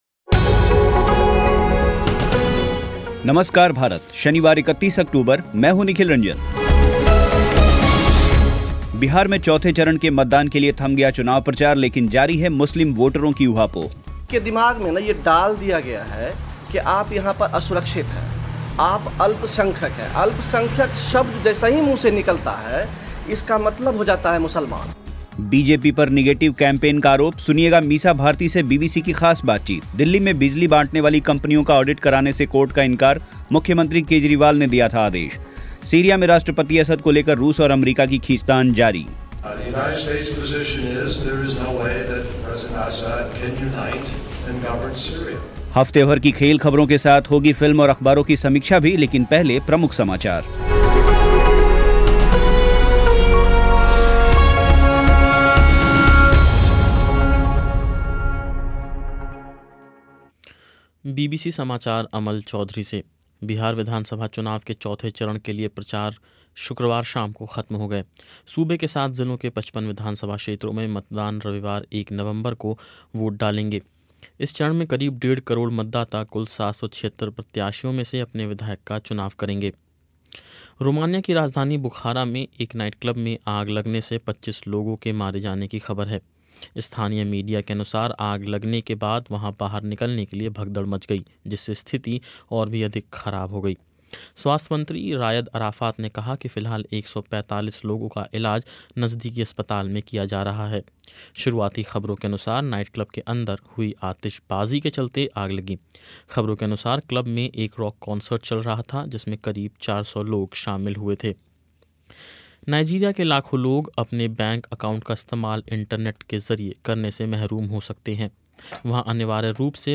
सुनिएगा मीसा भारती से बीबीसी की ख़ास बातचीत